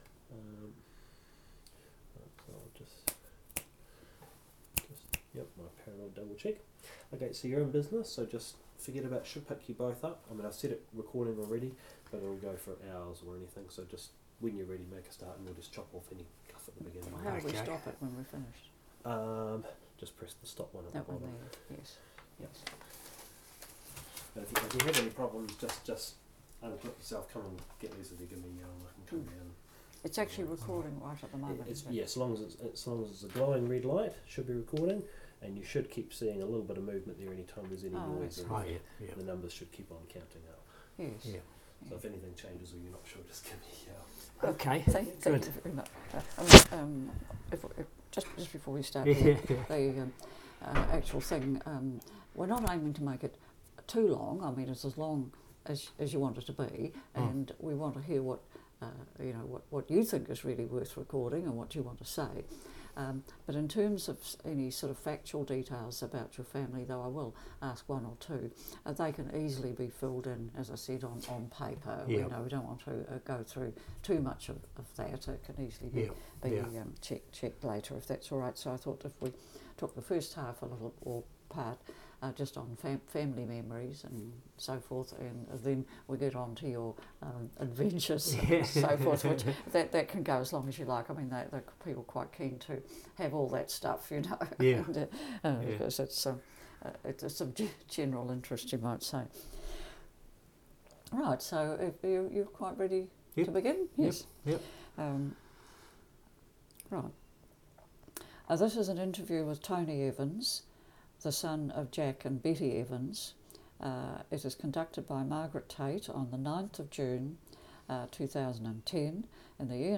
Oral Interview